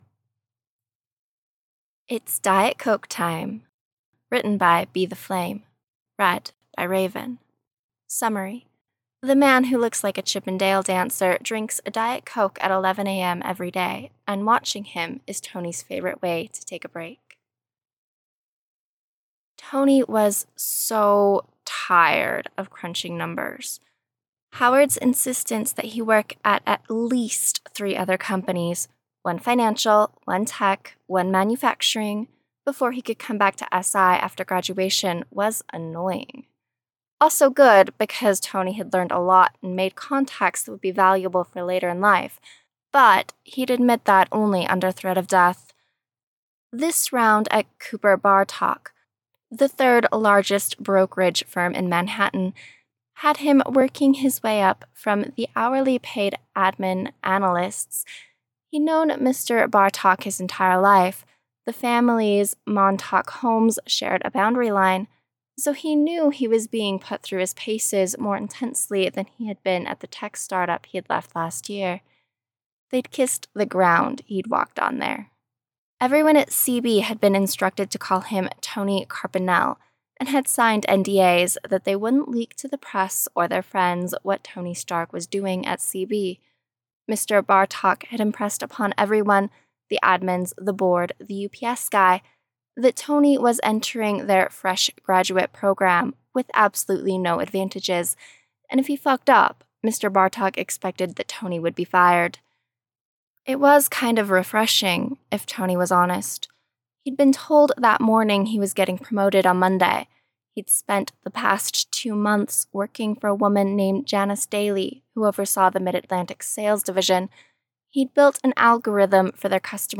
[Podfic] It's Diet Coke Time